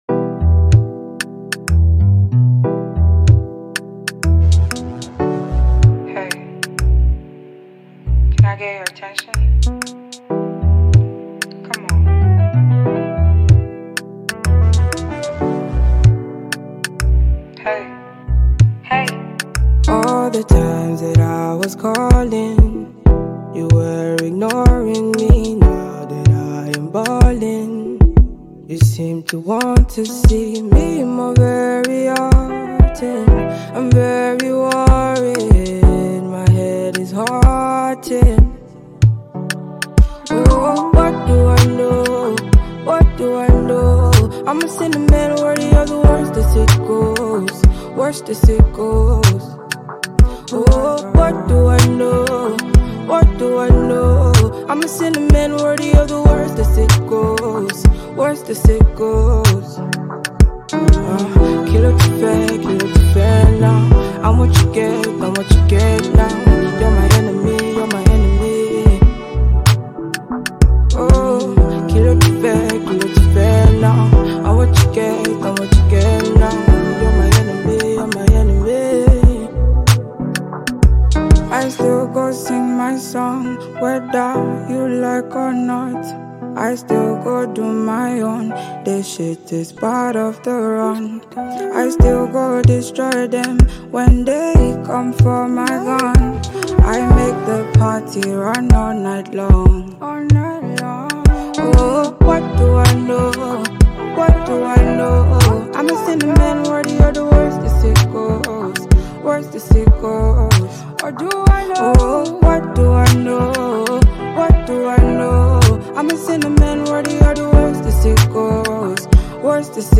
catchy and vibing single